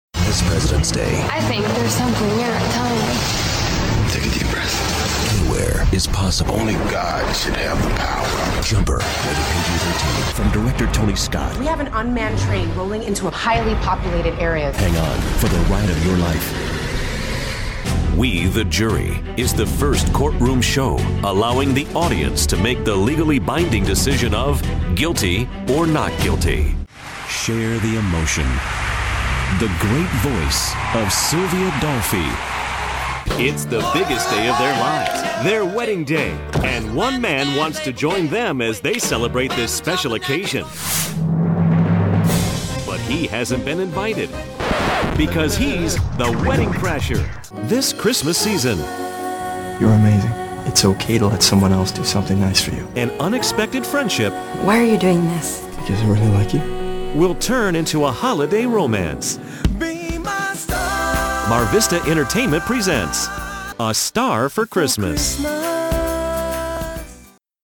middle west
Sprechprobe: Sonstiges (Muttersprache):